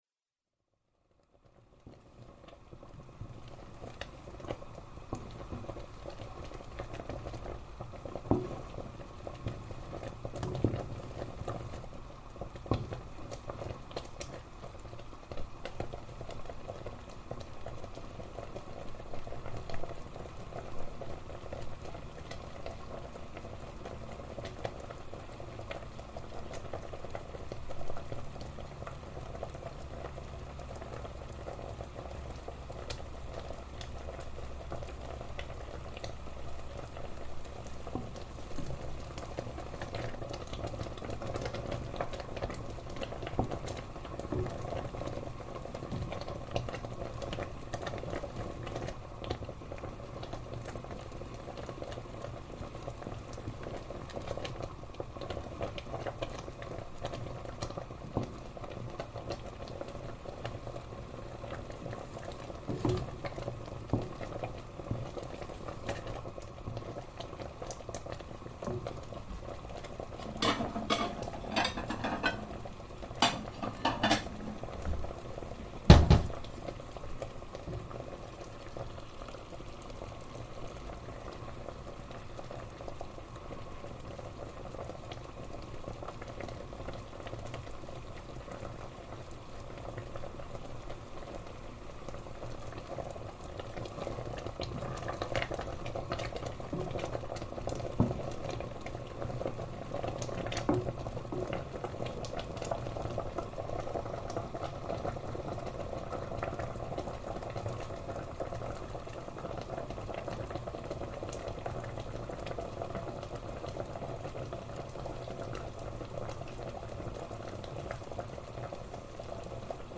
Rhubarb Jam - recorded for the creation of the Sonic Wallpaper piece to accompany SW 2097.